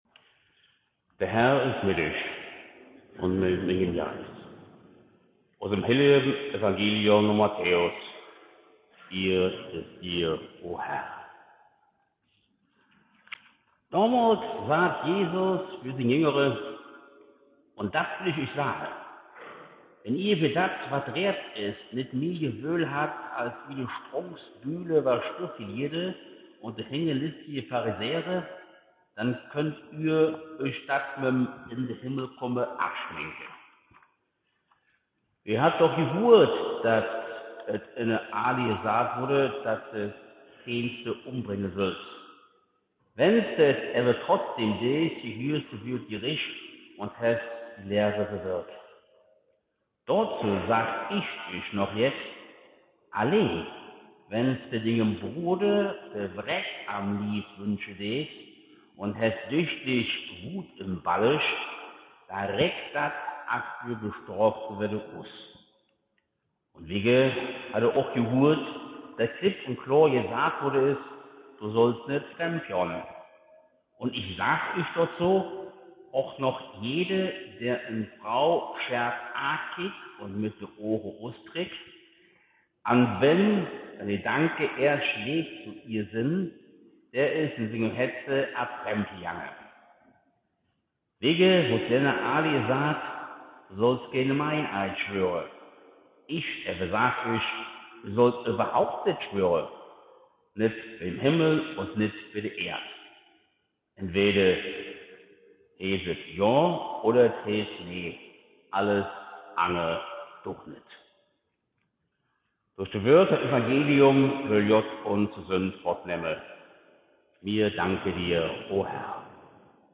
Predigt op Kölsch am 2. Sonntag der Weihnachtszeit zum Maiblömchen-Fest in Lich-Steinstraß